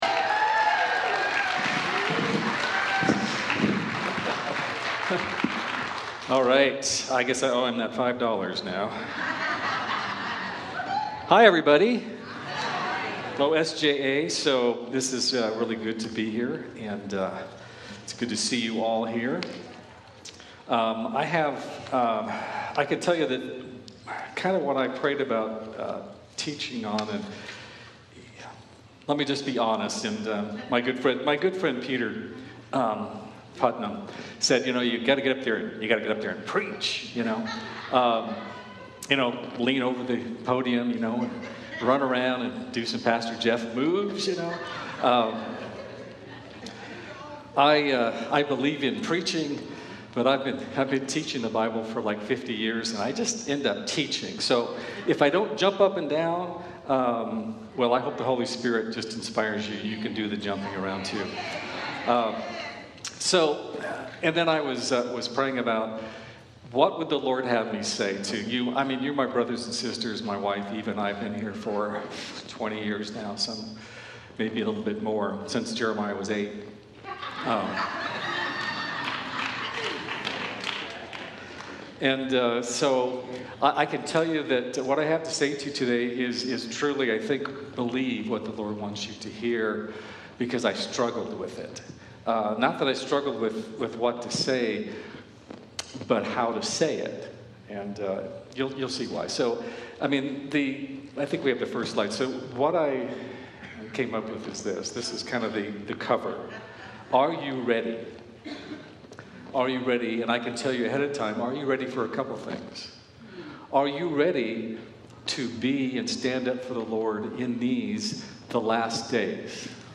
AYR24-SJA-Sermon.mp3